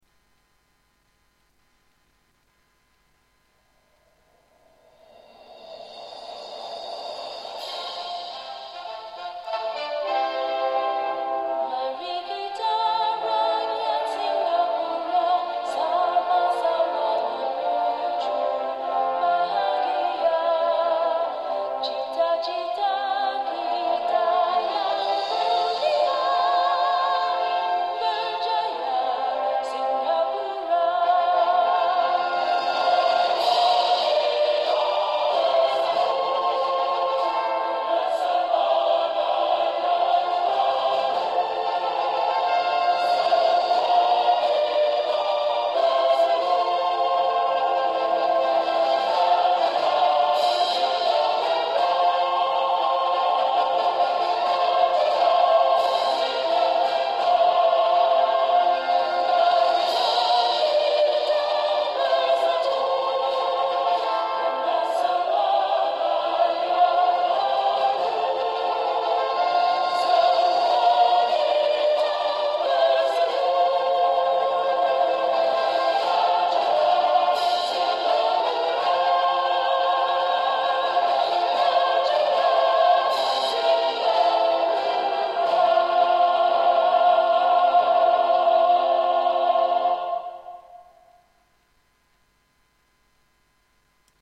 The national anthem of Singapore